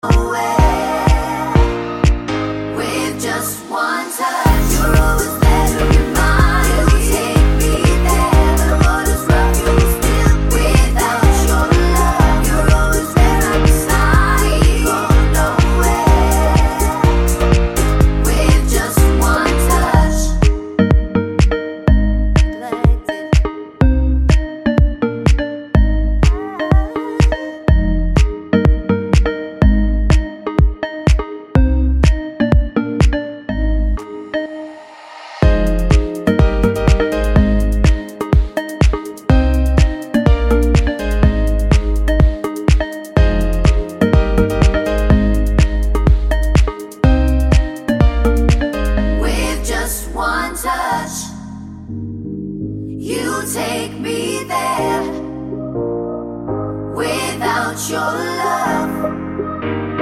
no Backing Vocals Pop (2010s) 3:18 Buy £1.50